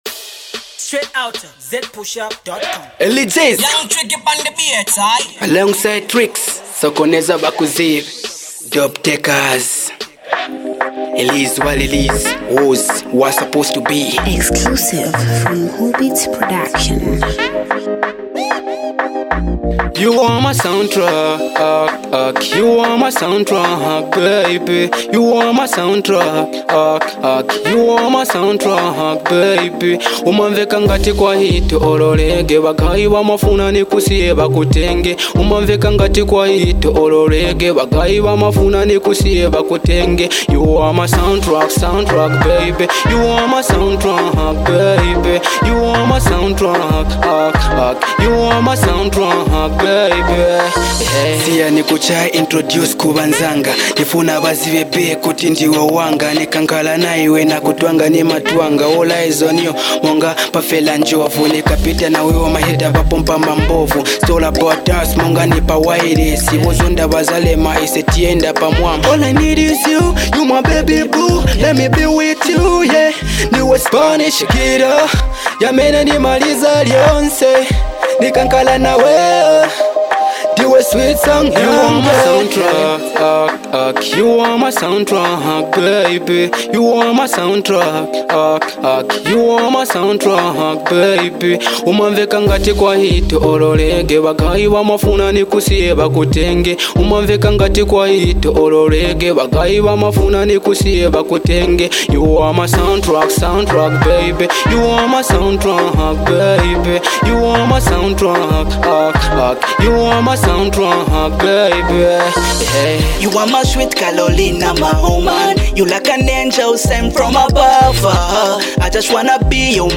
RnB joint